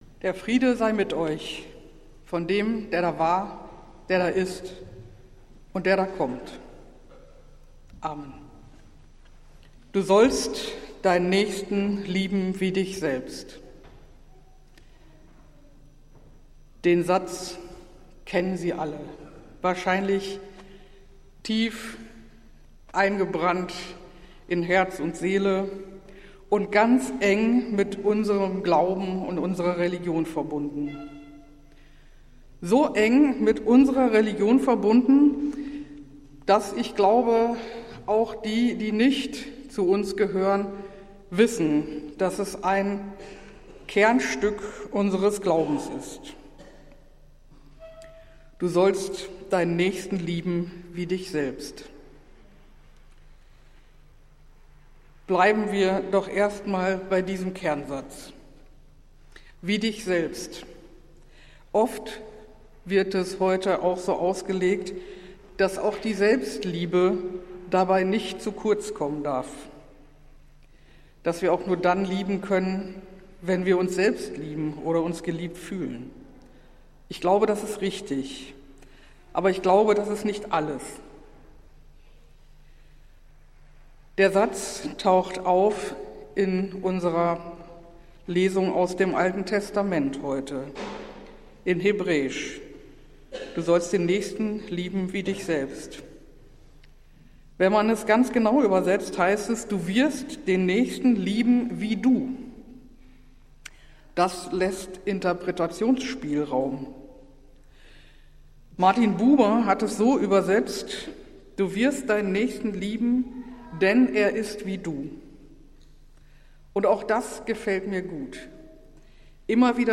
Predigt des Gottesdienstes aus der Zionskiche vom 14. September 2025